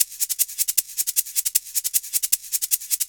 Shaker_Loop_B_155.wav